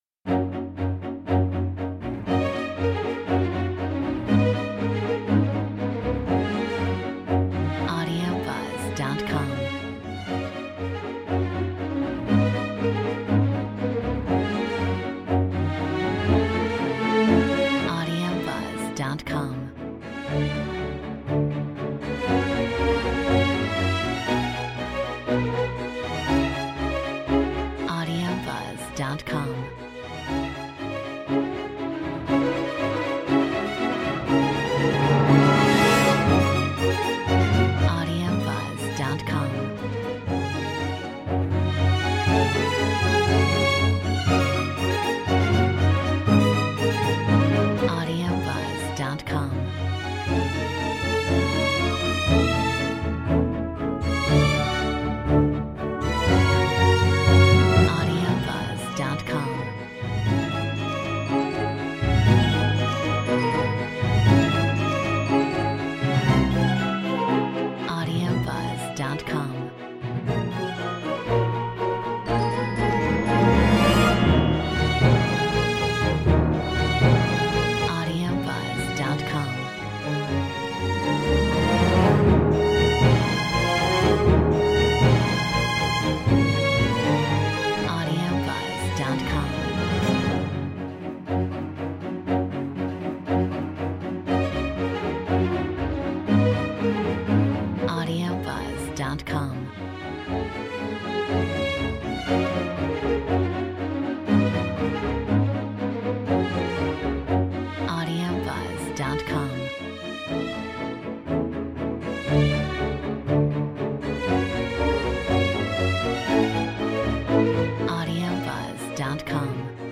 Metronome 120 BPM
Cinematic Production / Film Scores